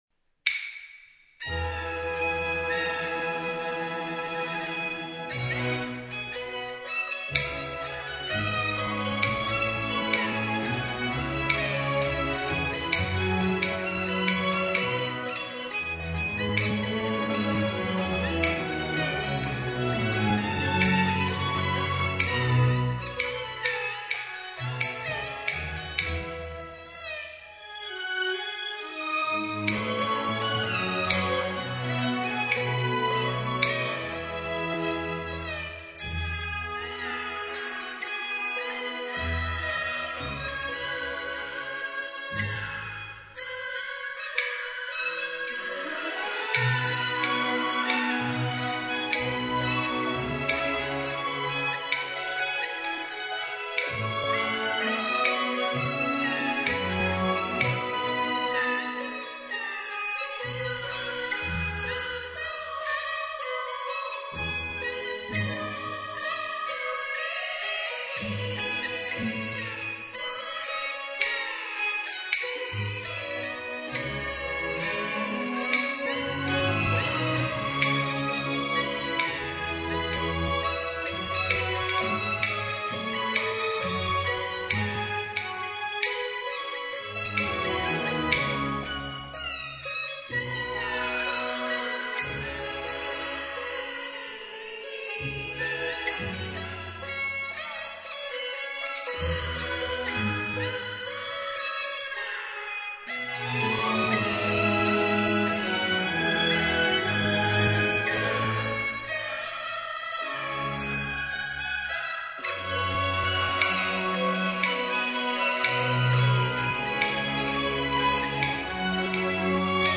试听曲 霸王别姬(南梆子 96K mp3pro)